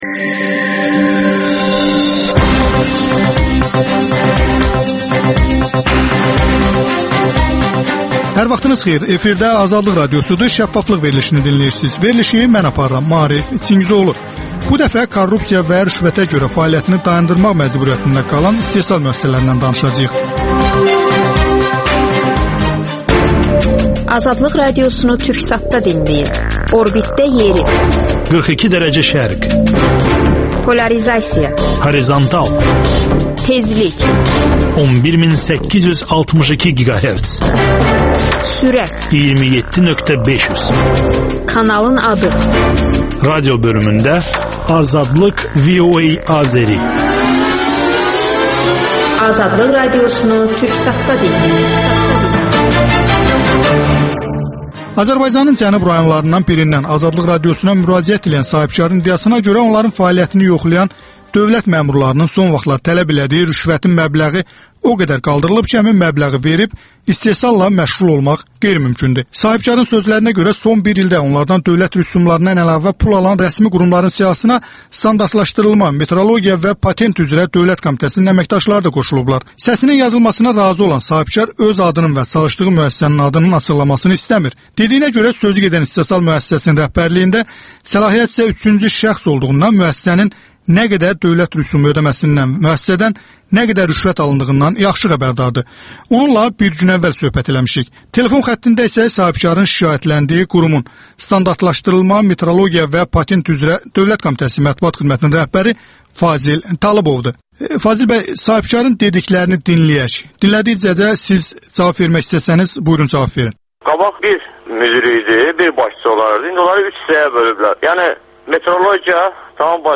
Radiodebat between official and entrepreneur